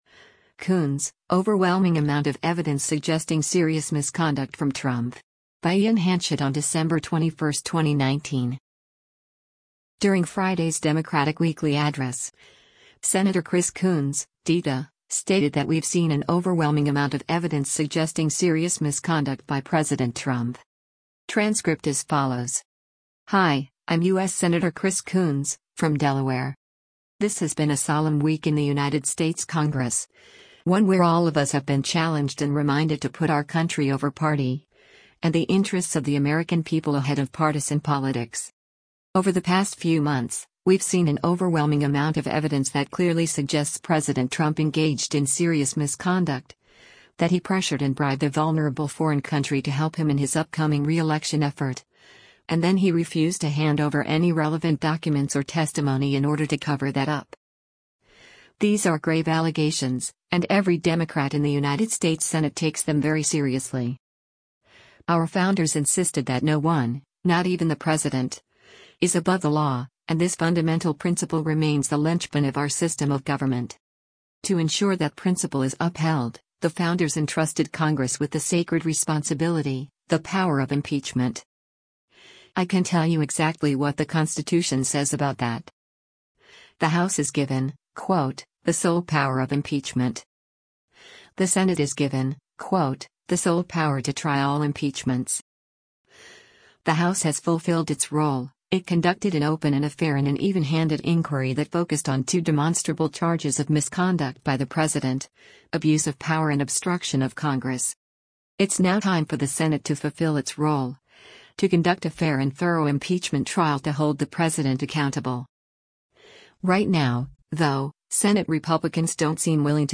During Friday’s Democratic Weekly Address, Senator Chris Coons (D-DE) stated that “we’ve seen an overwhelming amount of evidence” suggesting “serious misconduct” by President Trump.